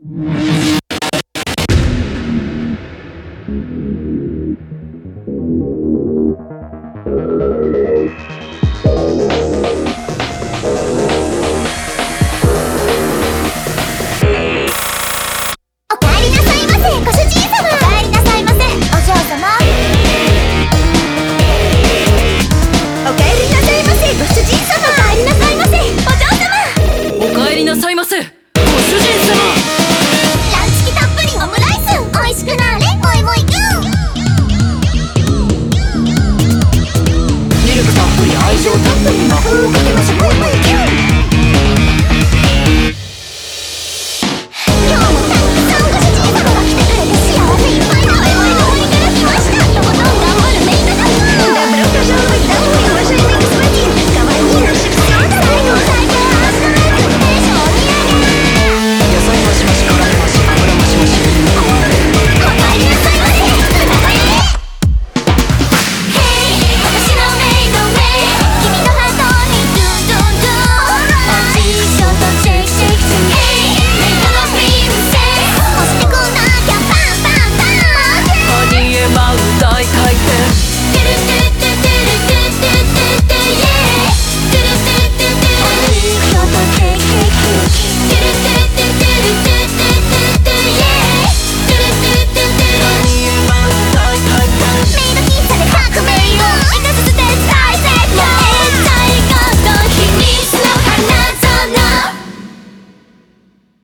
BPM134
Audio QualityPerfect (High Quality)
Comments🚨🚨UH OH weeb music alert 🚨🚨
A real mish mash of genres.
INDUSTRIAL HYPER MOE KYUN.